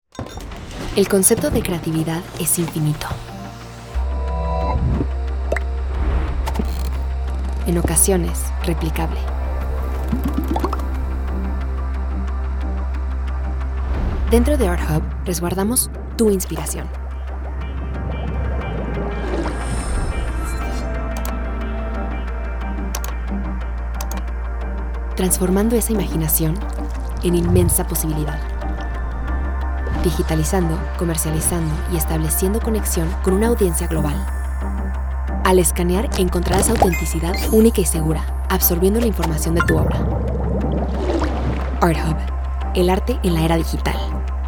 Erklärvideos
Kommerzielle Demo
Home Studio, Rode NT1 Kondensatormikrofon der 5. Generation